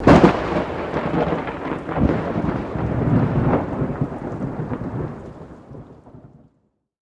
thundernew3.ogg